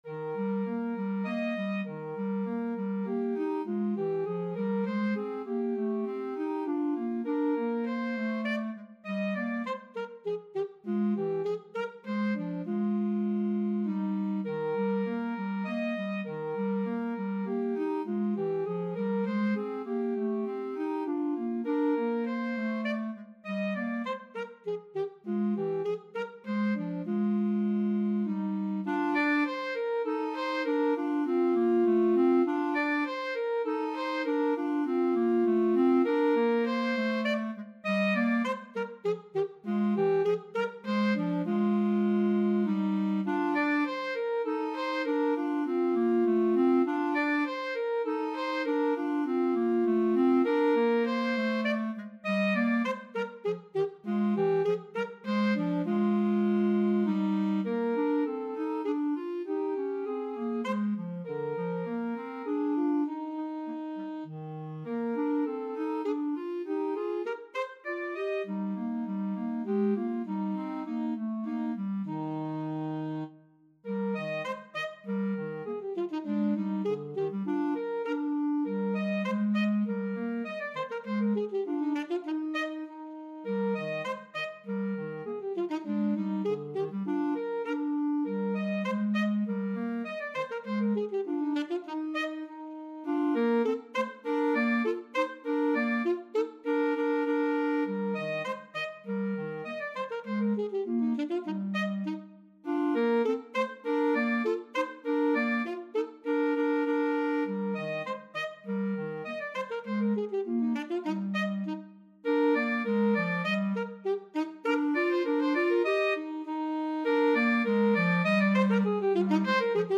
Free Sheet music for Clarinet-Saxophone Duet
Alto SaxophoneClarinet
Eb major (Sounding Pitch) F major (Clarinet in Bb) (View more Eb major Music for Clarinet-Saxophone Duet )
3/4 (View more 3/4 Music)
Cantabile
Classical (View more Classical Clarinet-Saxophone Duet Music)